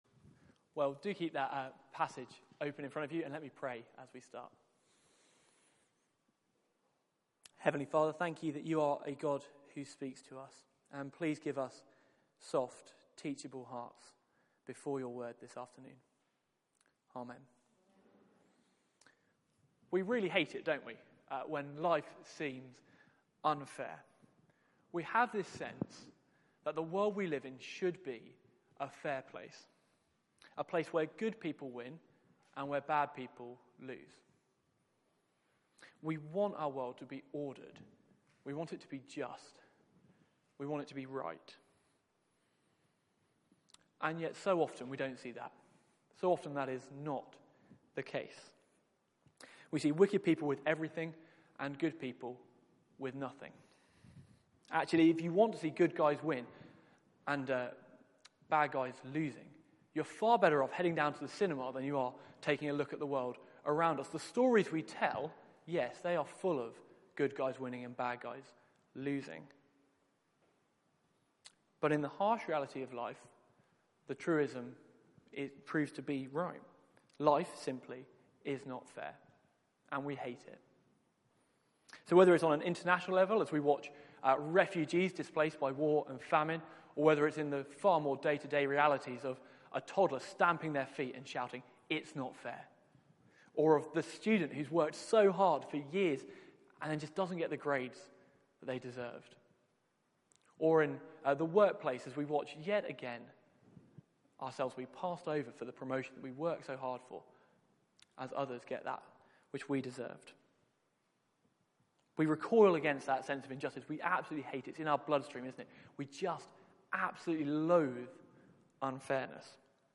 Media for 4pm Service on Sun 21st Aug 2016 16:00 Speaker
Summer Songs Theme: The God of justice Sermon Search the media library There are recordings here going back several years.